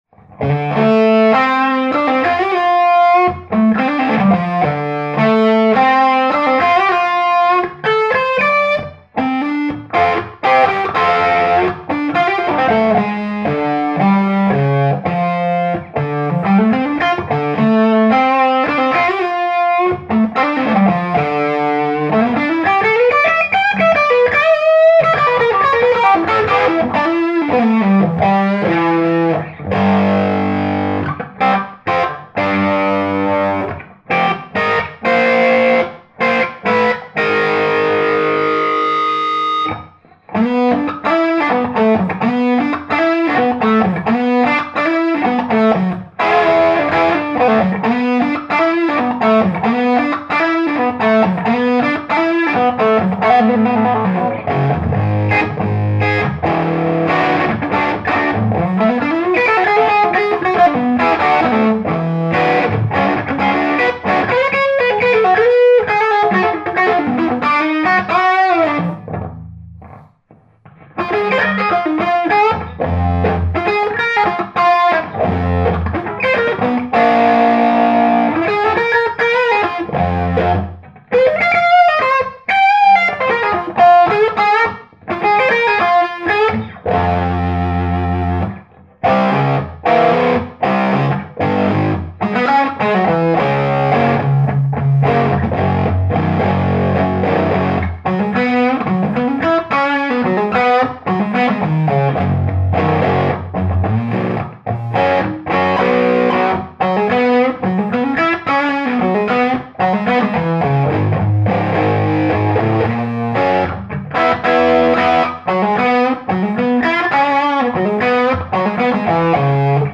My Fuchs doing Robben tone.
Me recording with handheld Sony PCM recorder.
It did have a bright cap. This was recorded with a handheld. I was a little off axis. Amp is plenty bright.
The bottom on this clip and RFs Dumble are very very close.....